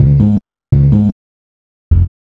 2008L B-LOOP.wav